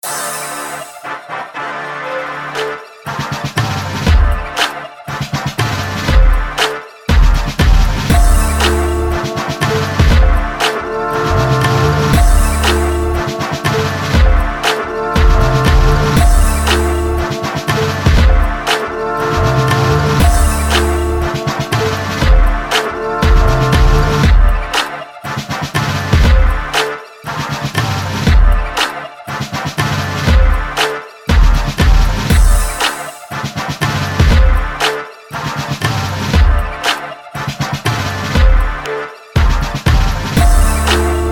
Miami Club Type Beats
Club Banger  Beat